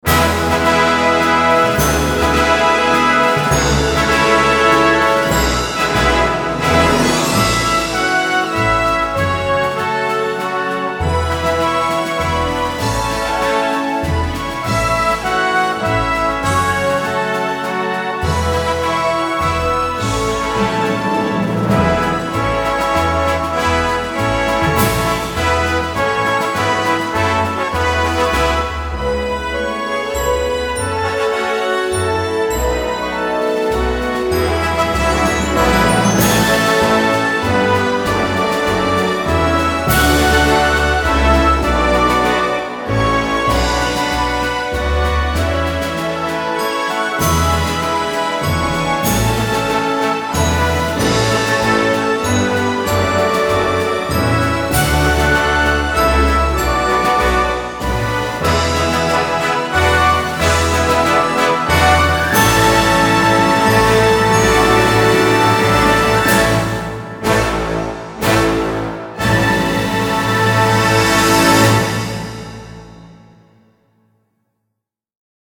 в оркестровом исполнении